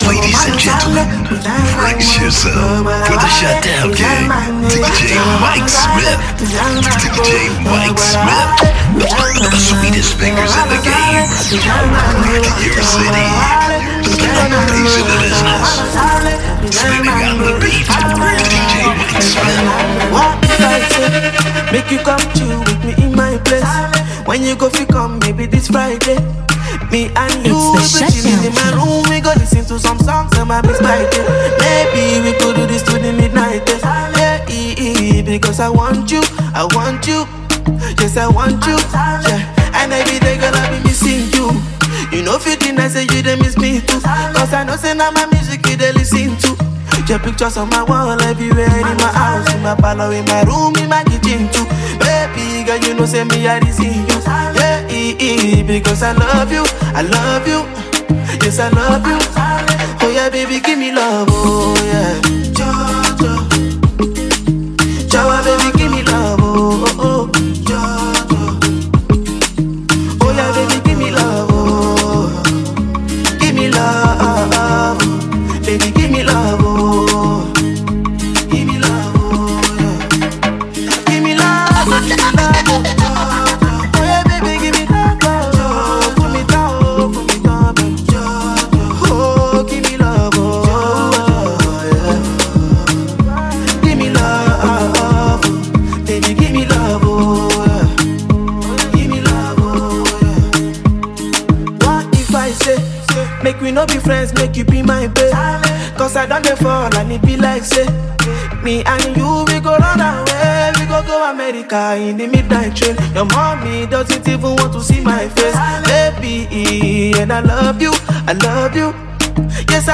Ghanaian disc jockey